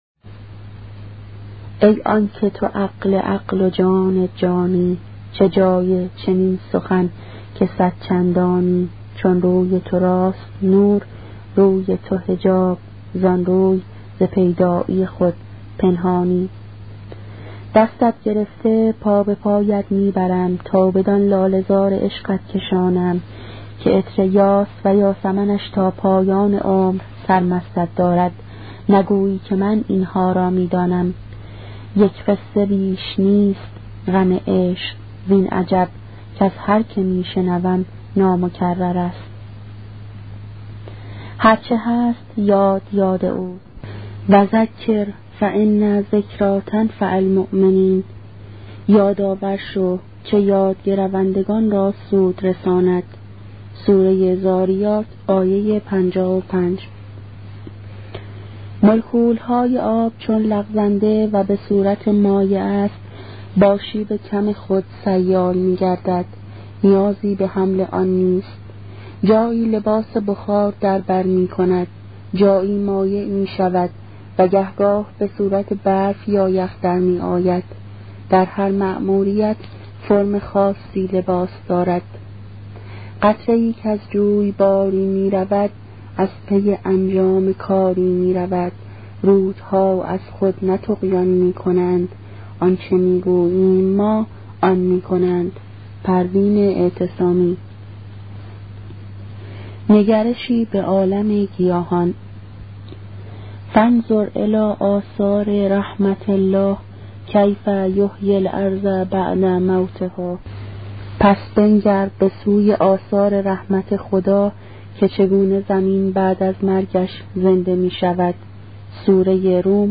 کتاب صوتی عبادت عاشقانه , قسمت دوم